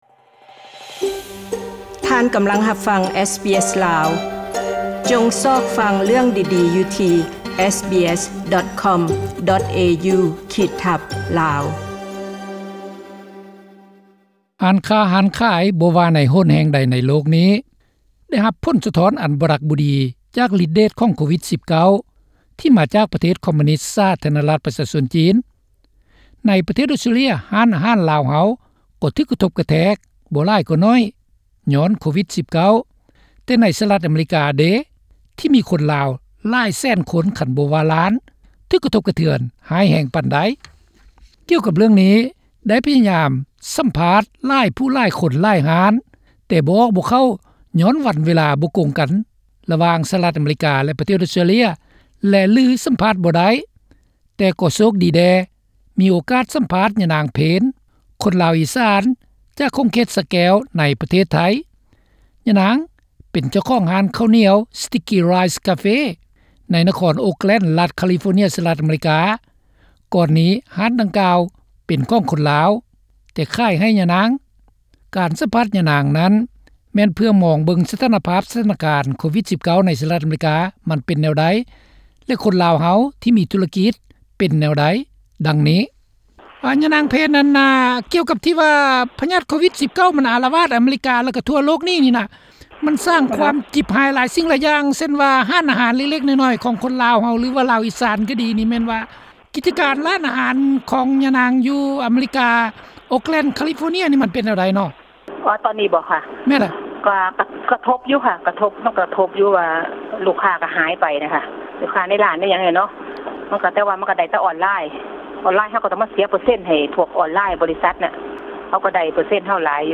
ທຸຣະກິດຂັ້ນນ້ອຍລາວໃນສະຫະຣັດຢູ່ລອດແບບໃດກັນ? (ສຳພາດ)